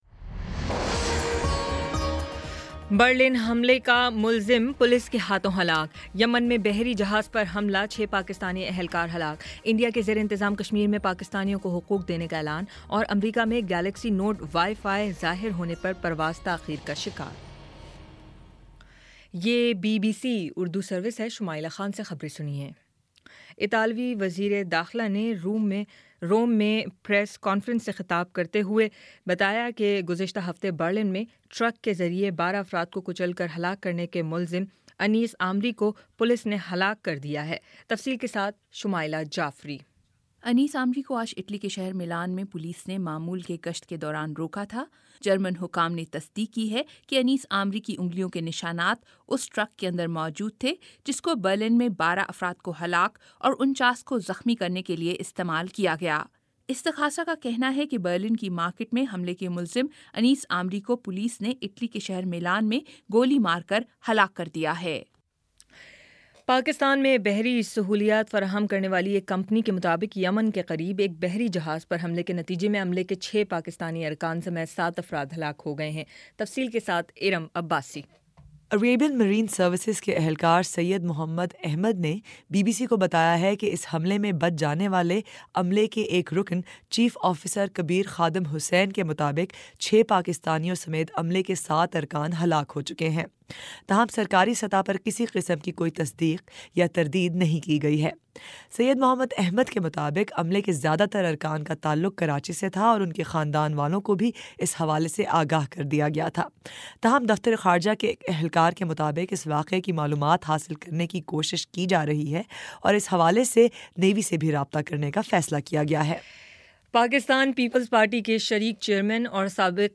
دسمبر 23 : شام پانچ بجے کا نیوز بُلیٹن